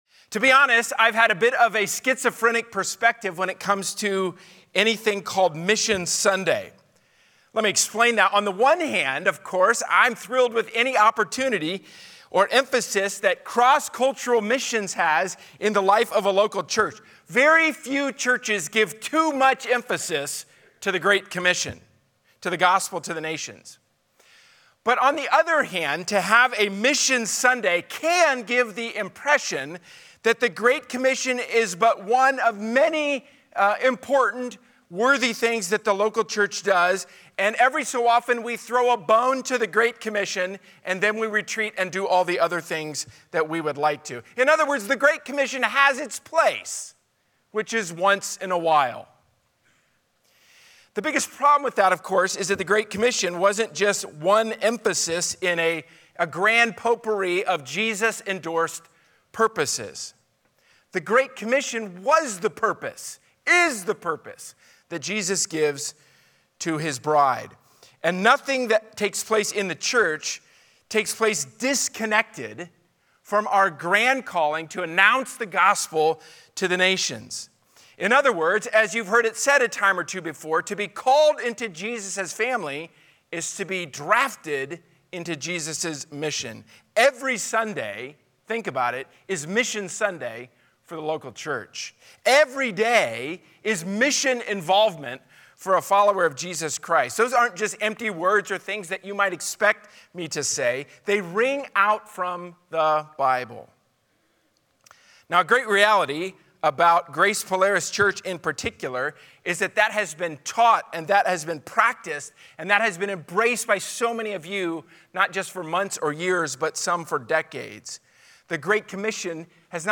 Sermon: "All Glory be to Christ!" from Guest Speaker • Grace Polaris Church